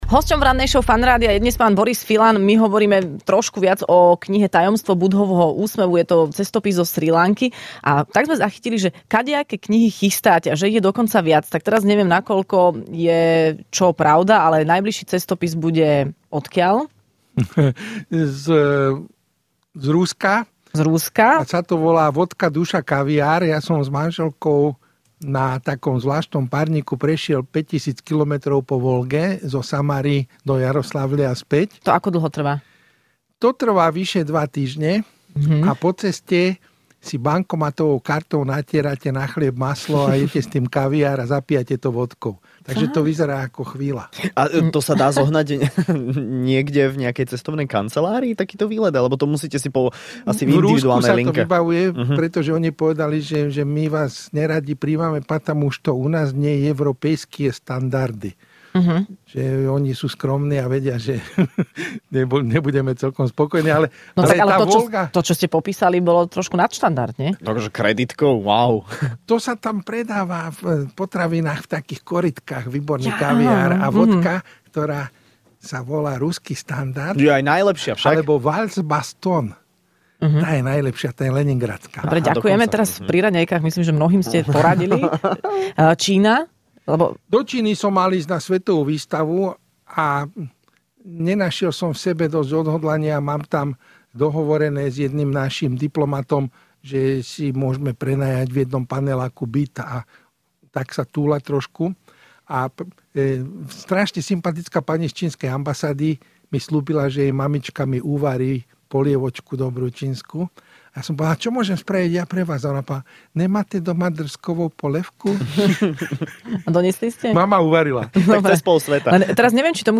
Hosťom v Rannej šou bol cestovateľ a spisovateľ Boris Filan, ktorý dostal aj ocenenie